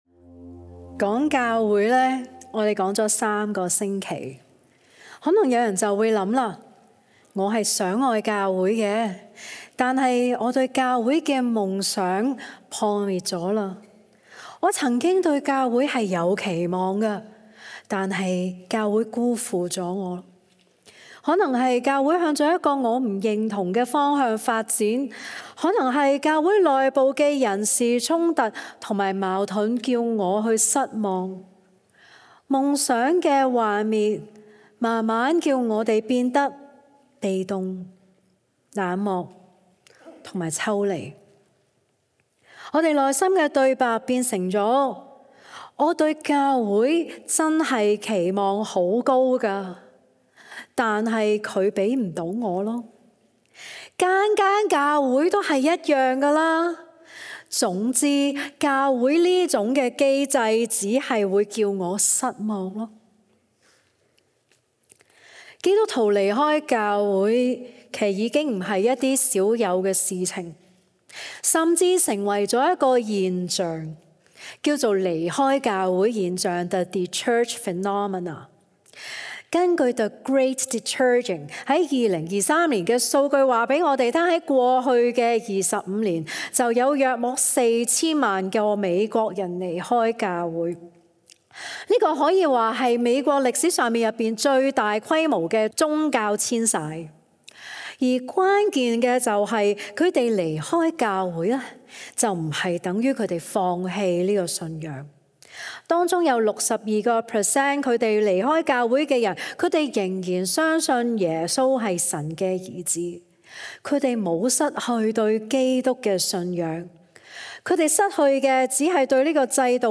講道